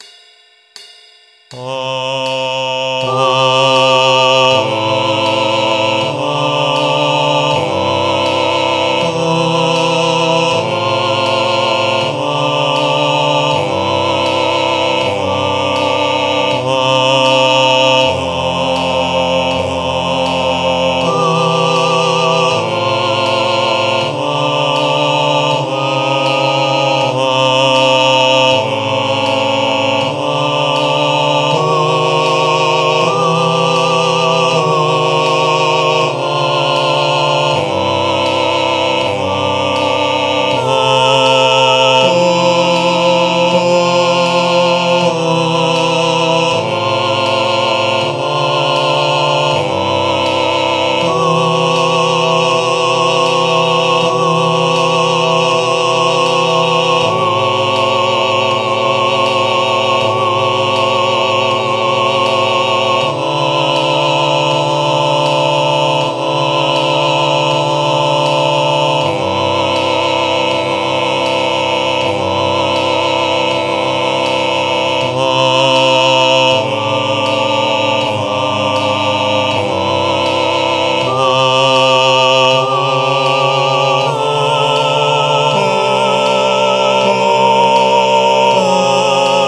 I’ve included files with a drone of the key center.
Soprano and Bass Only with Drone
MP3 with Soprano and Bass only with Cymbal